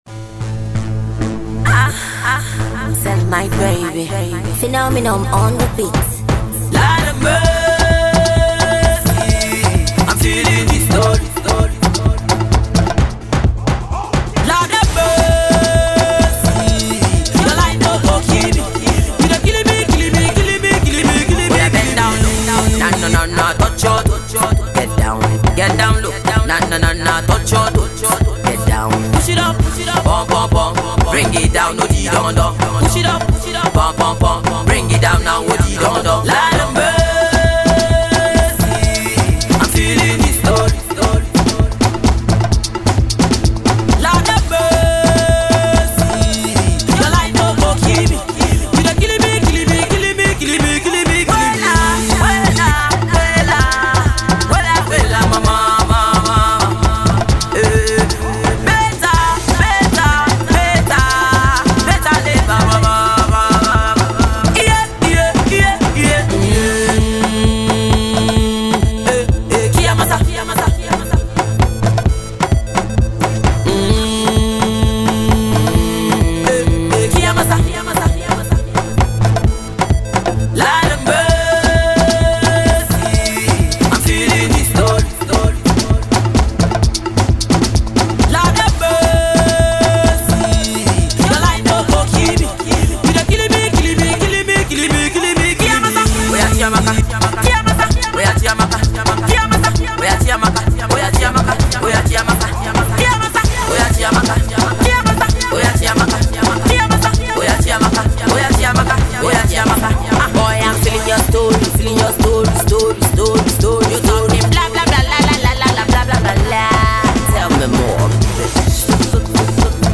super club banger